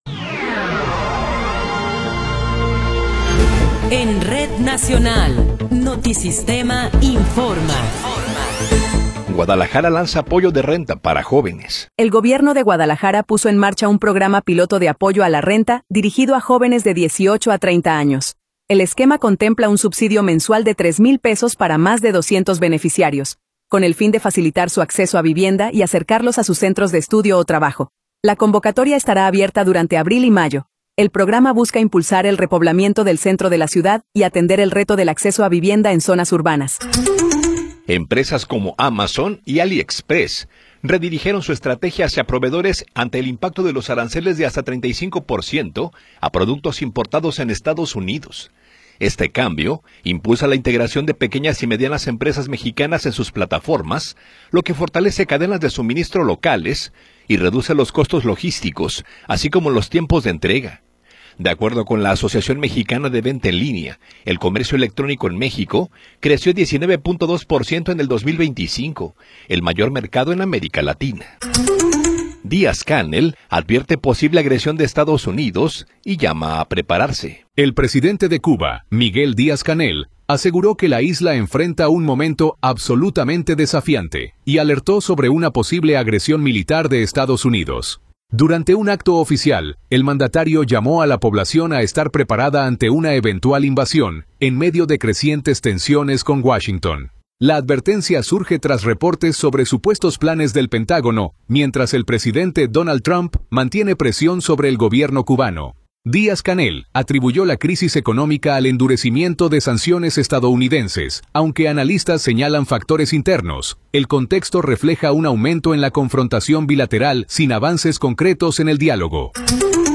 Noticiero 19 hrs. – 25 de Abril de 2026
Resumen informativo Notisistema, la mejor y más completa información cada hora en la hora.